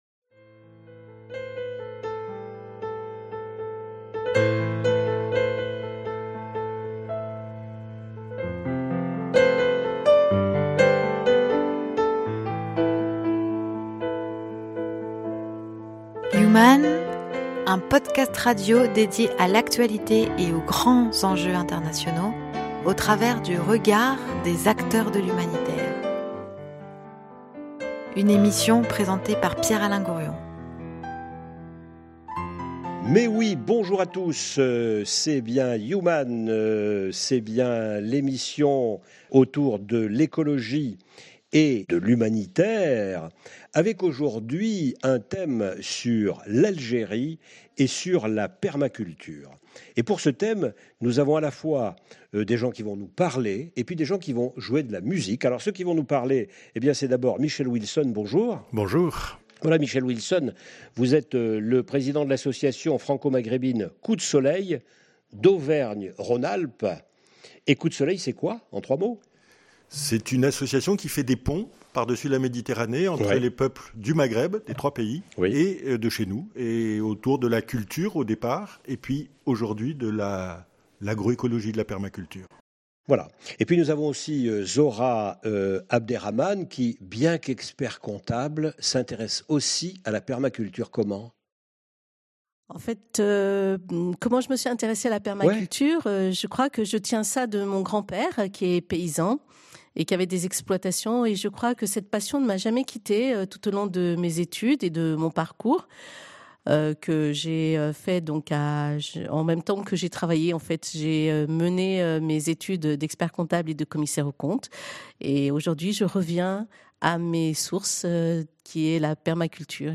Pour le premier anniversaire du « Hirak » algérien, une table ronde musicale "Chaabi" en live
Mandole
Derbouka